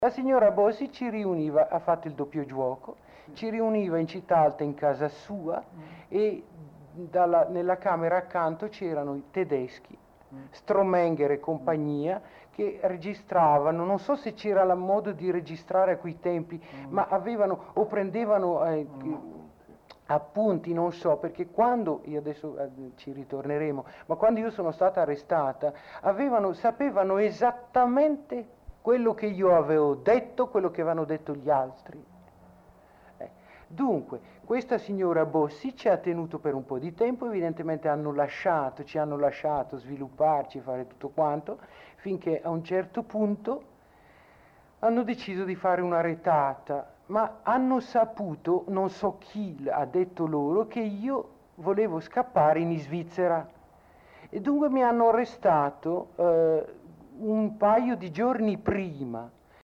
Testimonianza